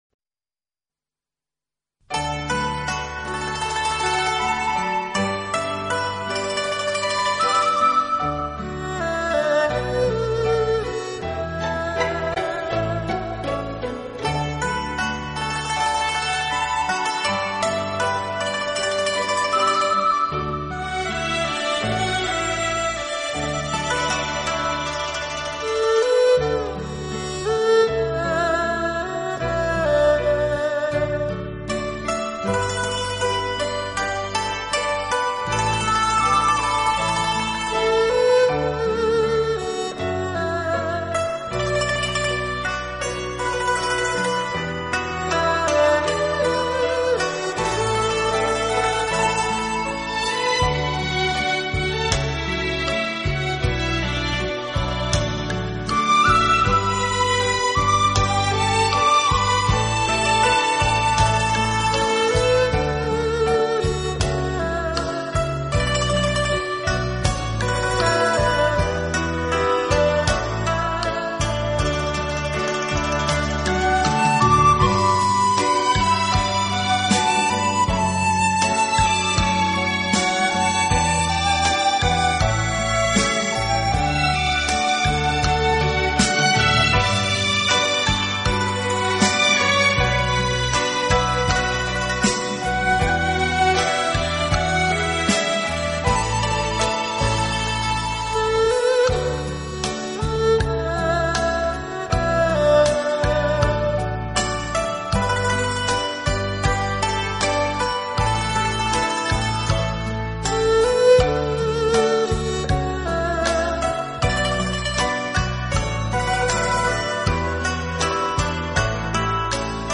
专辑歌手：纯音乐
民族乐器与电子乐的完美结合，演绎着一首首经典动听老歌，顾名思义“怀旧篇”。